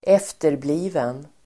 Ladda ner uttalet
efterbliven adjektiv (äldre uttryck), backward , mentally retarded Uttal: [²'ef:terbli:ven] Böjningar: efterblivet, efterblivna Definition: psykiskt utvecklingsstörd Exempel: ett efterblivet barn (a retarded child)